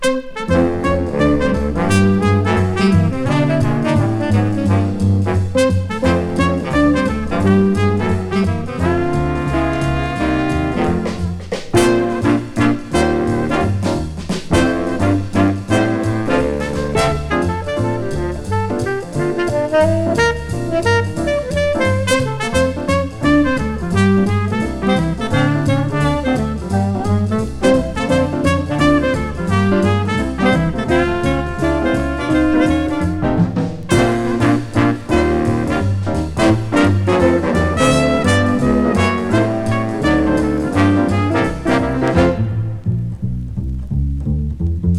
通して演奏アレンジ良くポップな聴き心地で、小粋なモダンジャズを展開。
Jazz　USA　12inchレコード　33rpm　Mono